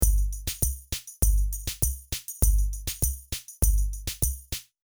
FishMarket_hat-and-kik-and-tom-and-tamb.mp3